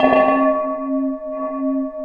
77 CUP ECHO.wav